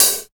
118 HAT 2.wav